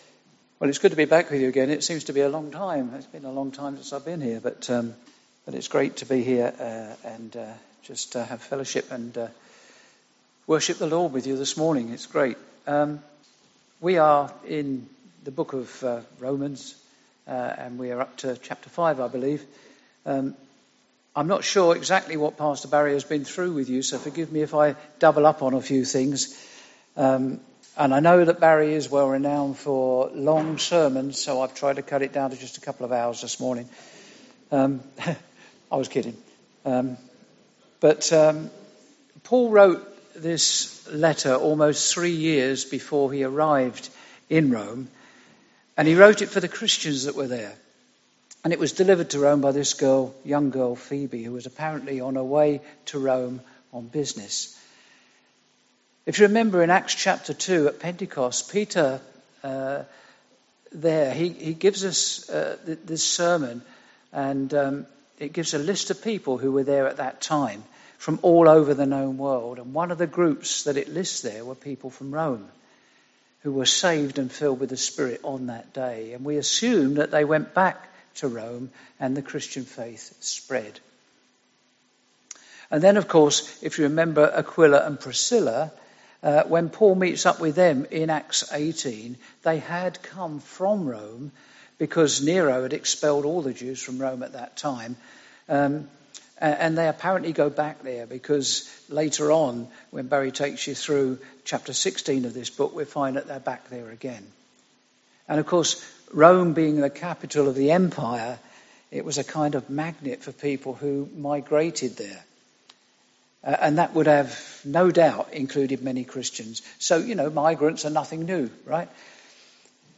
Series: Guest Speakers , Sunday morning studies Tagged with guest speakers , verse by verse